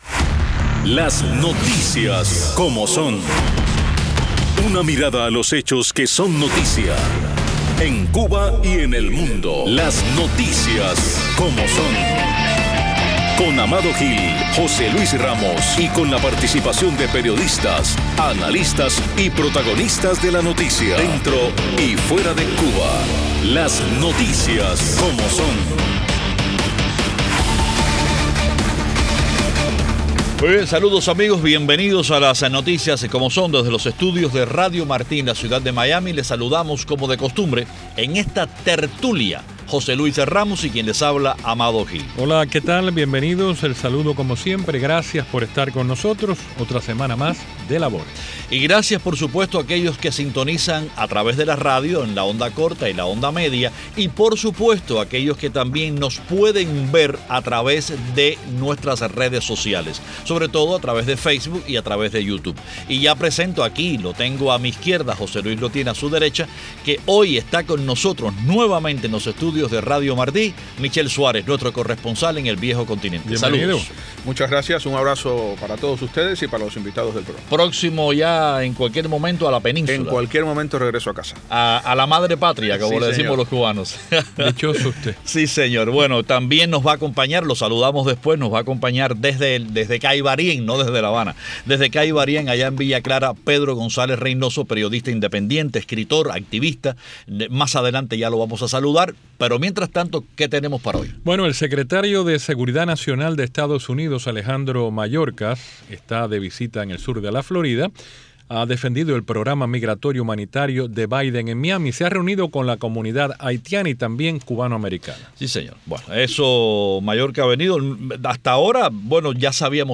En la Tertulia de LNS: 🔷 El secretario de Seguridad Nacional Alejandro Mayorkas defiende el programa migratorio humanitario de Biden en Miami 🔷 Unos 1.400 cubanos han llegado a EEUU bajo el nuevo programa de parole humanitario